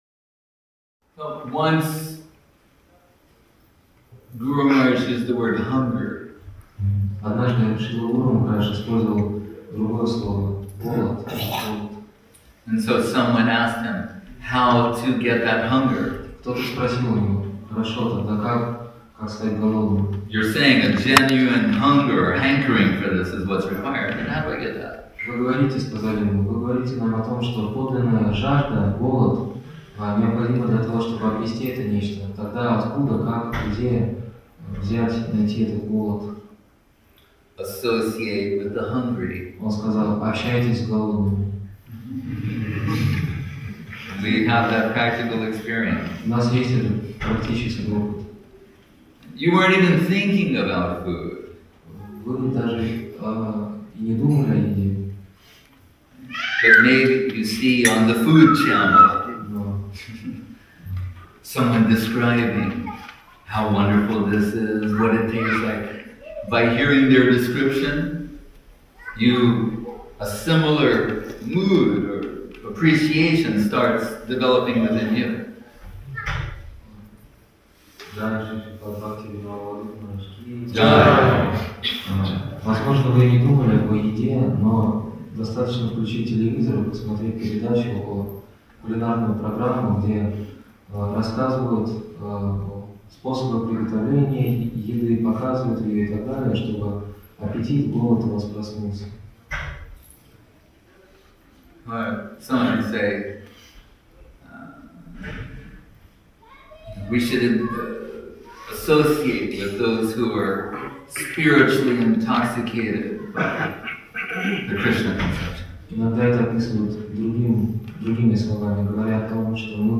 Place: Sri Chaitanya Saraswat Math Saint-Petersburg